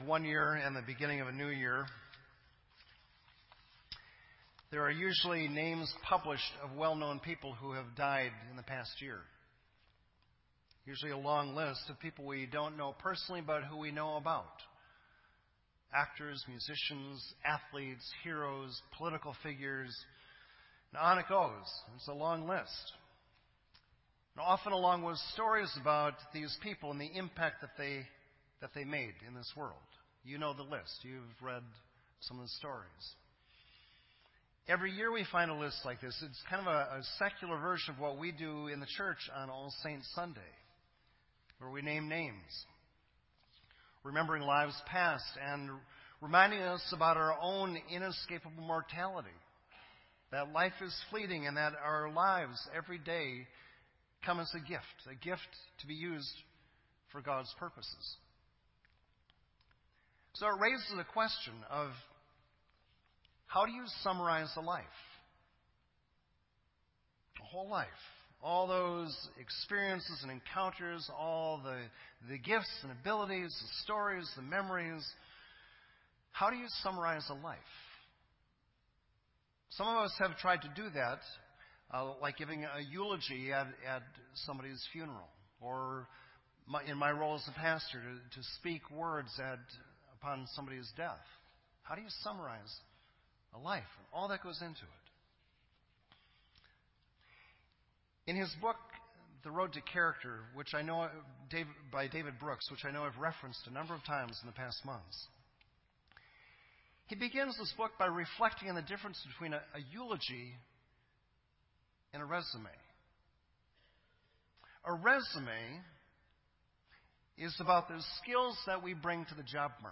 This entry was posted in Sermon Audio on January 3